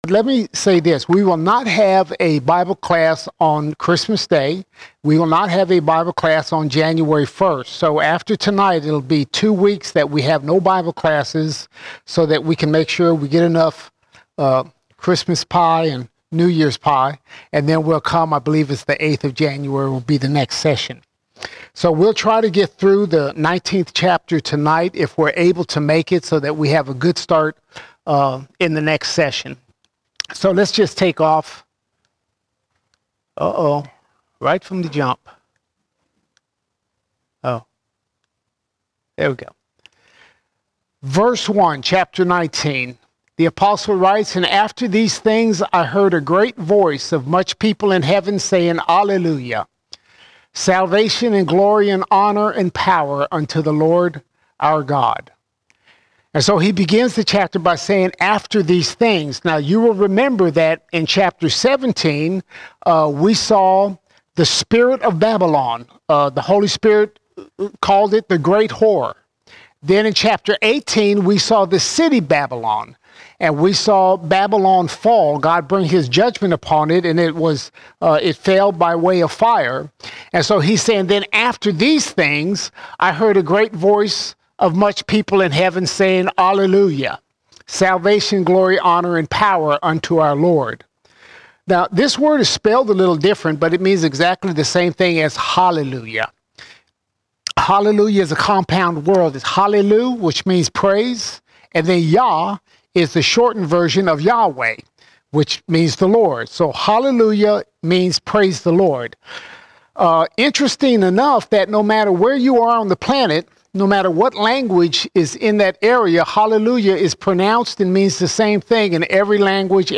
19 December 2024 Series: Revelation All Sermons Revelation 19:1 to 20:3 Revelation 19:1 to 20:3 We arrive at the Marriage Supper of the Lamb.